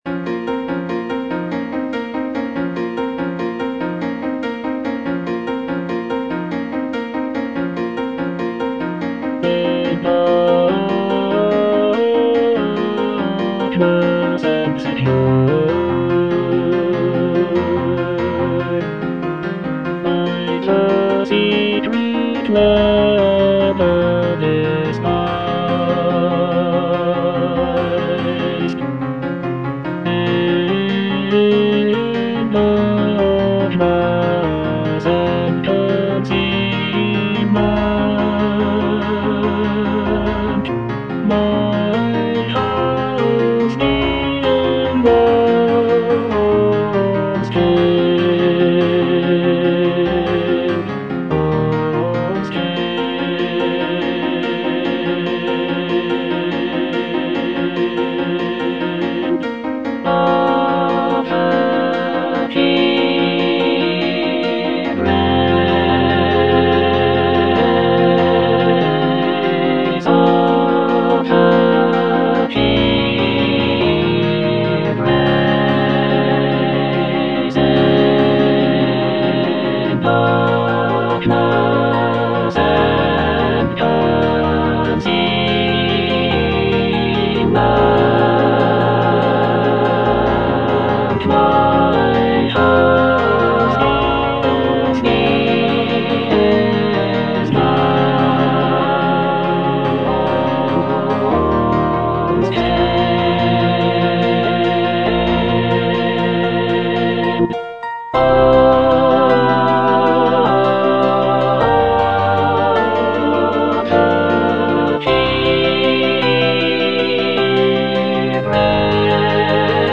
All voices
is a choral work